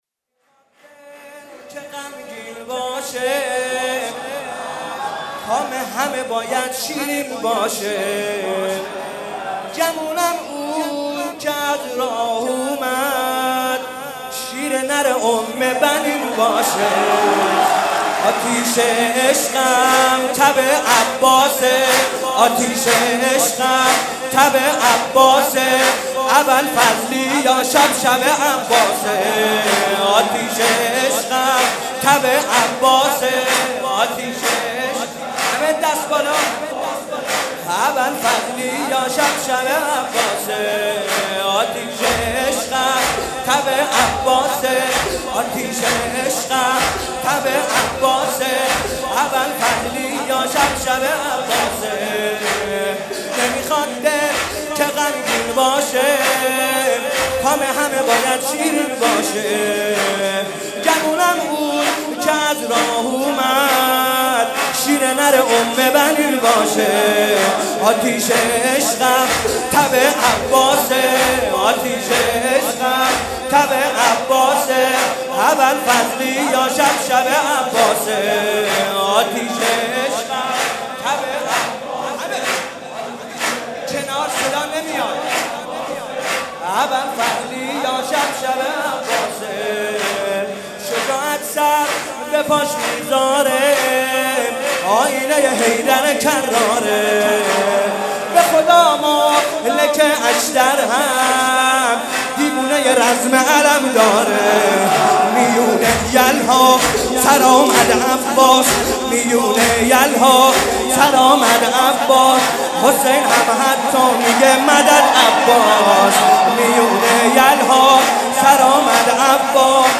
قالب : سرود